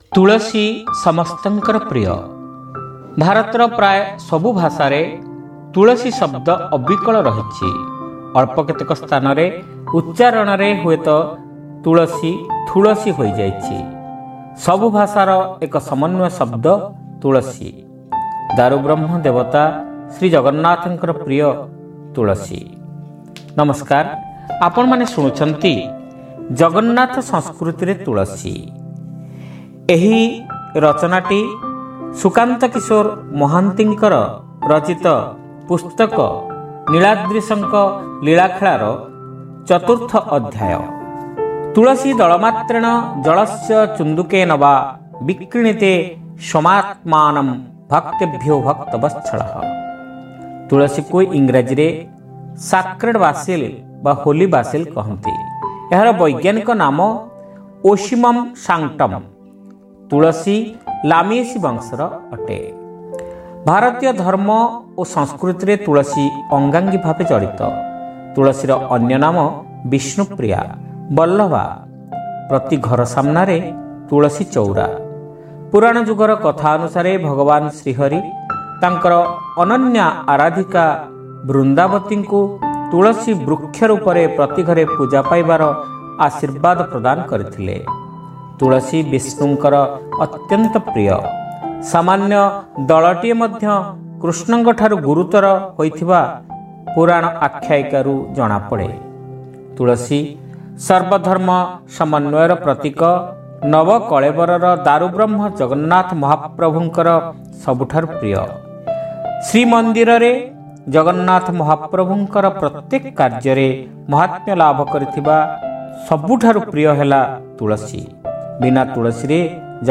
ଶ୍ରାବ୍ୟ ଗଳ୍ପ : ଜଗନ୍ନାଥ ସଂସ୍କୃତିରେ ତୁଳସୀ